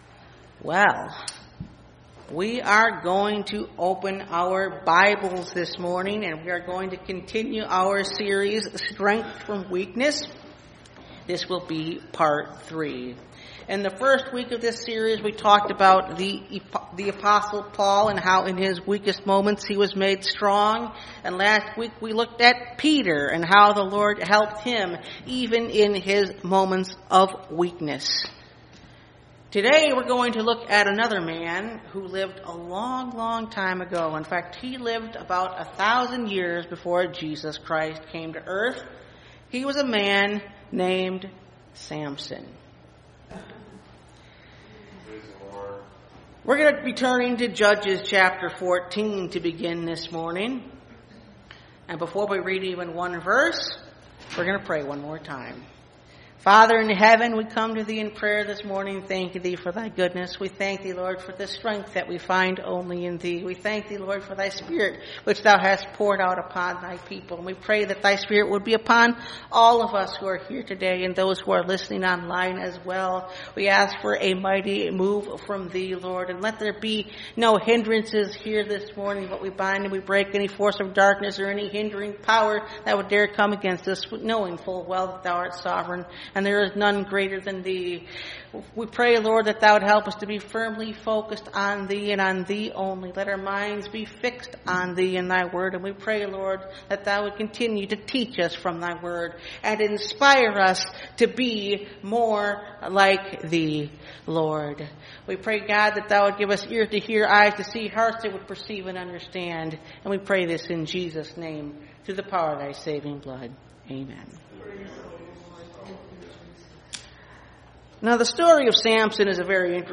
Strength From Weakness – Part 3 (Message Audio) – Last Trumpet Ministries – Truth Tabernacle – Sermon Library
Service Type: Sunday Morning